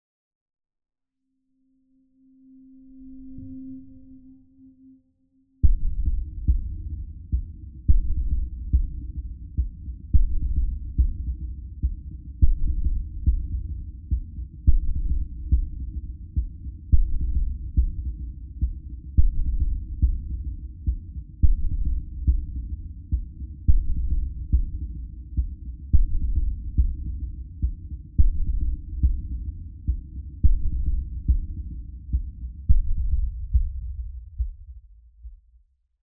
STest1_Mono200Hz.wav